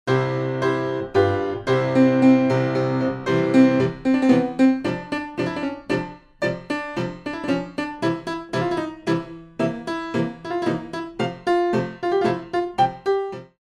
Classical Arrangements for Pre Ballet Classes